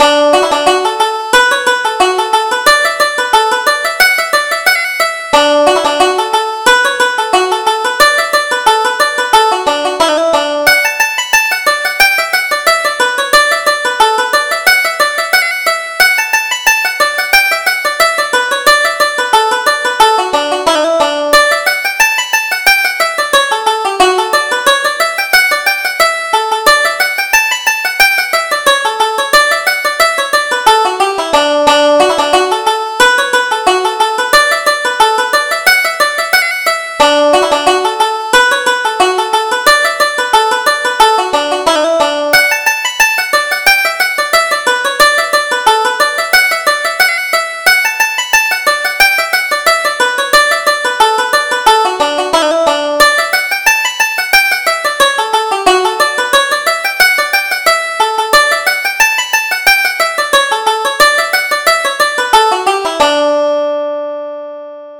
Reel: Miss Monaghan